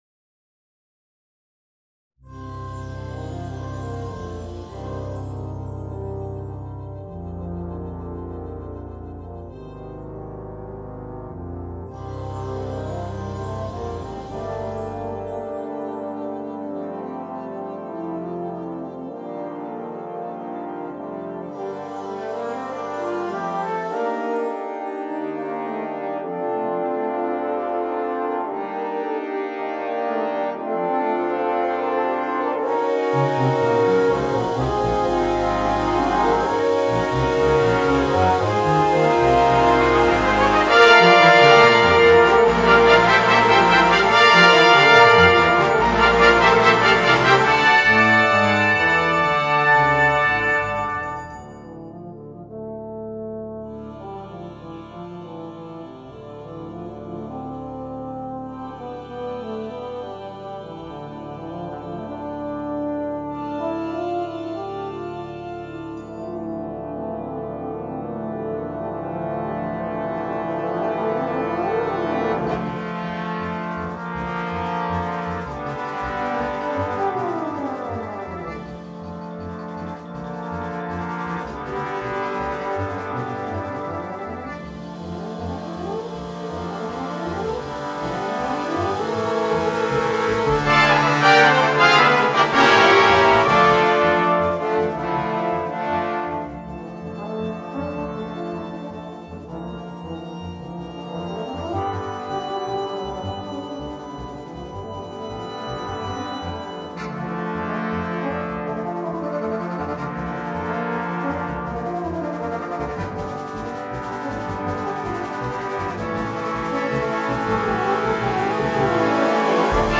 Voicing: Bb Bass and Brass Band